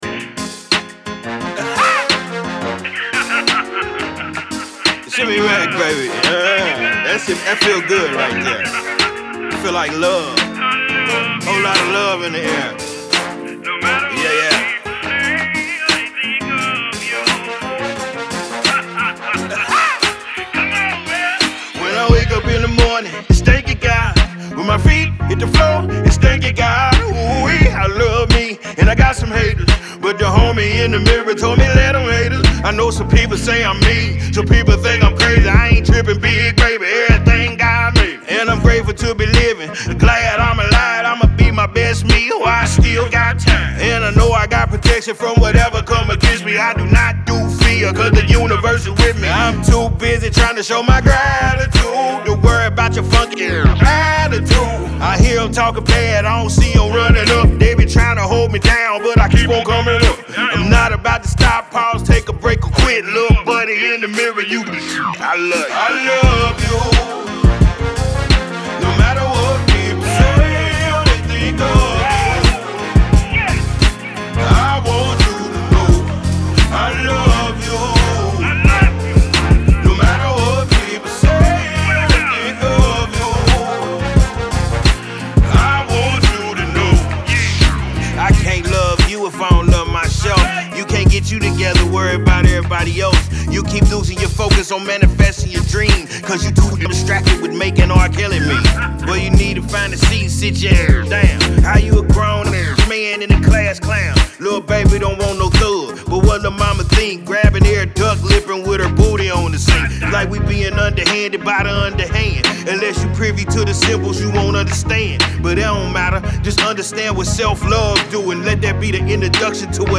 Hiphop
uplifting